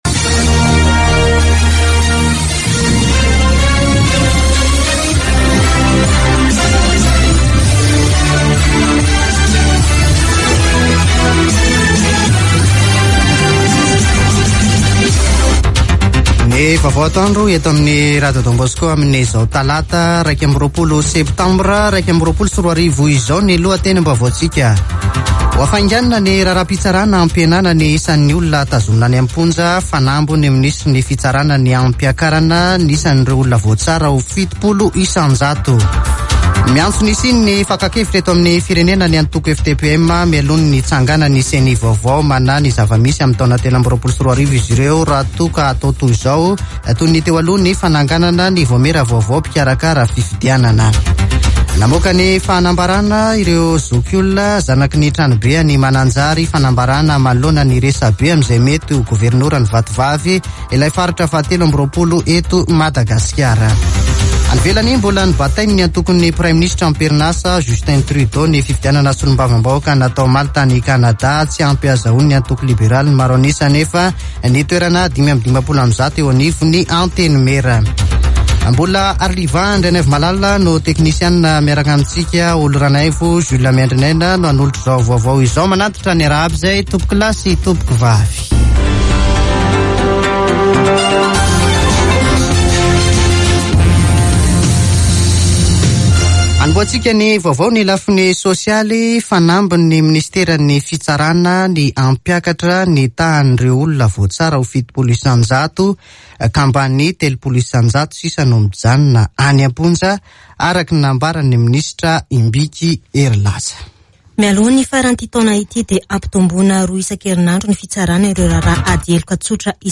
[Vaovao antoandro] Talata 21 septambra 2021